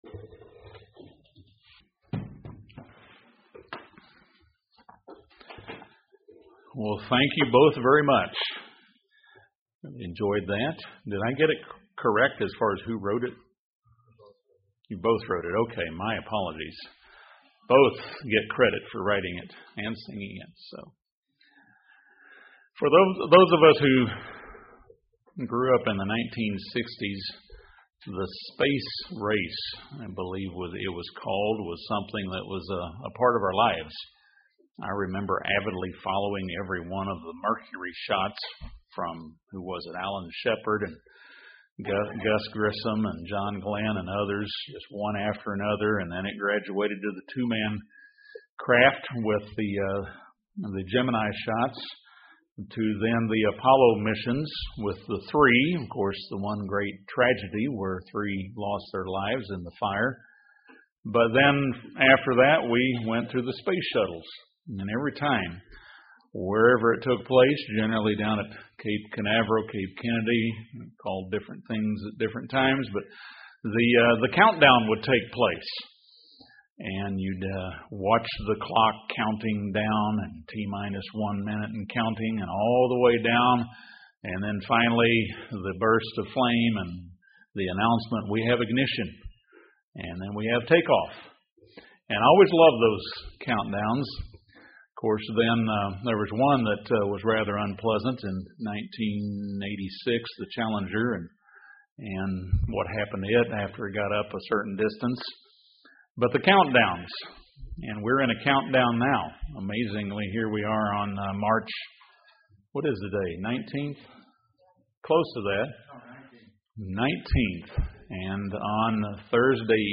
The Passover and Days of Unleavened Bread are almost here. This sermon offers several points for spiritually and physically preparing for the spring festival season.